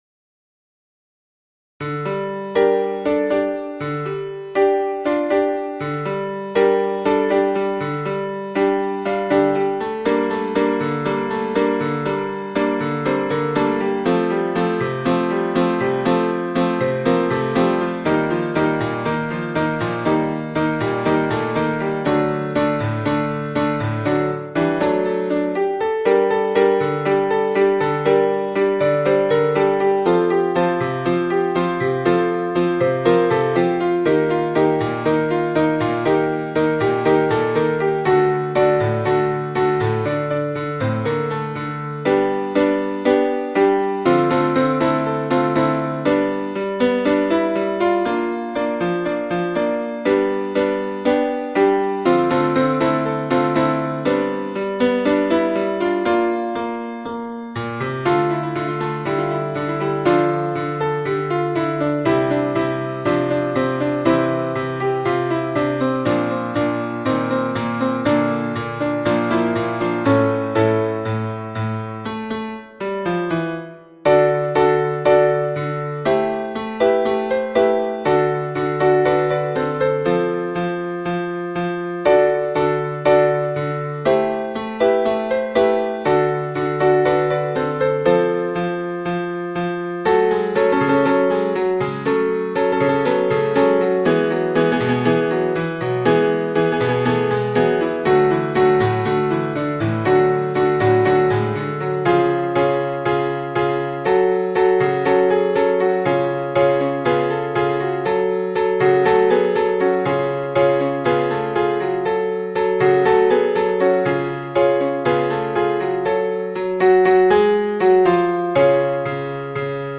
Happy_together-tutti-piano.mp3